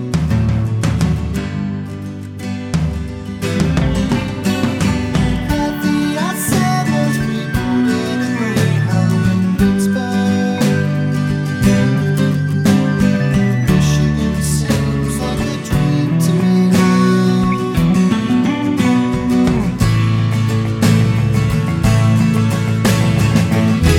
No Harmony Pop (1960s) 3:23 Buy £1.50